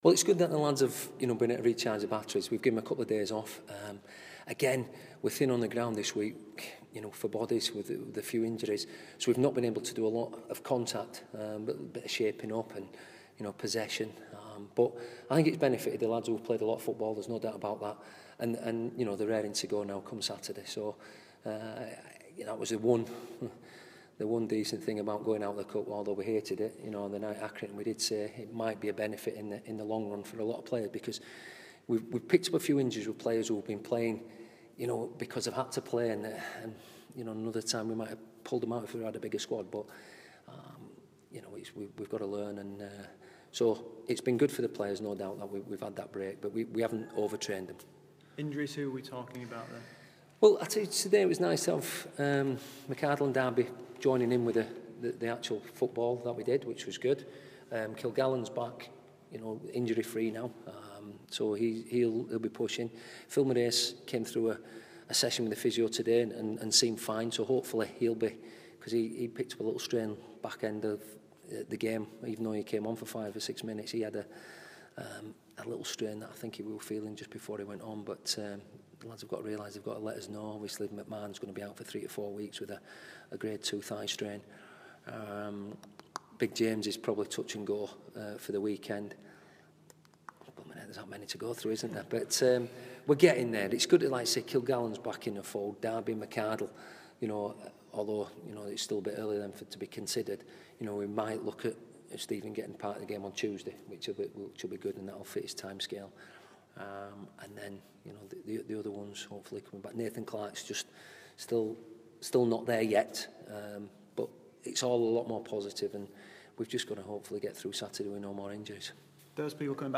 Bradford City boss Stuart McCall talks to members of the media